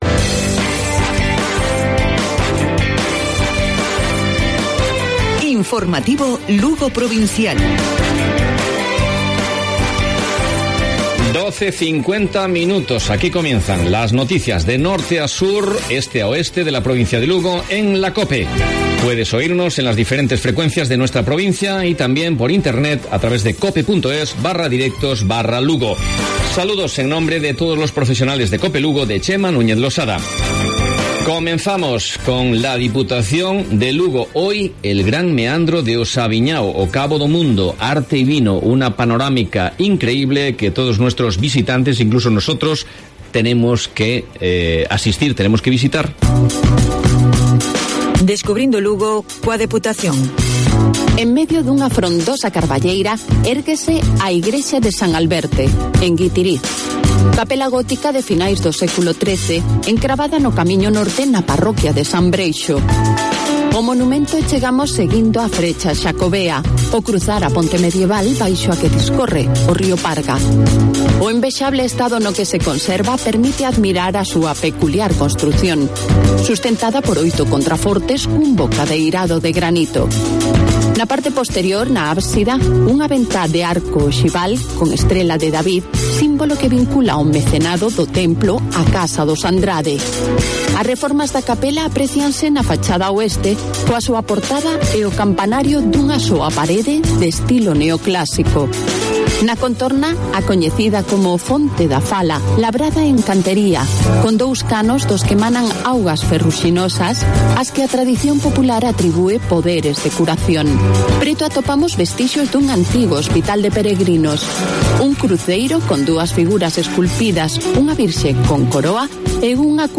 Informativo Provincial Cope Lugo. Miércoles, 12 de agosto 12:50-13:00 horas